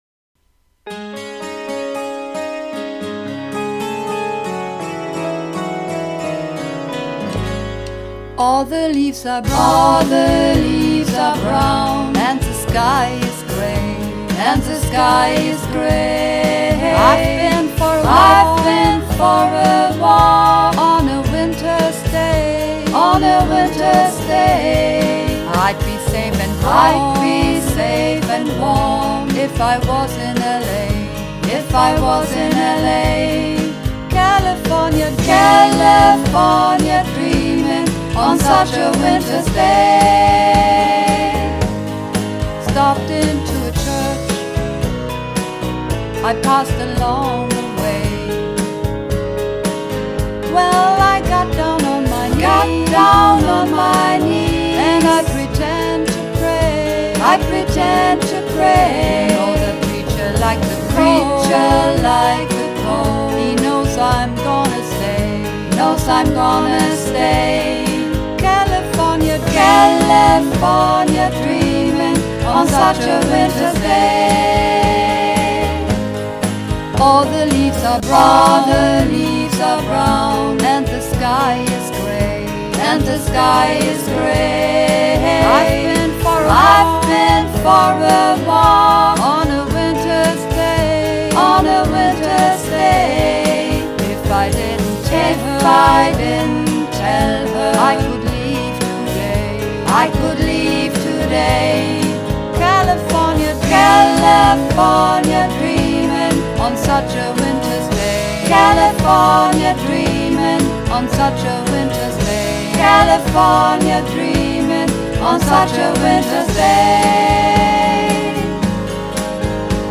Mehrstimmig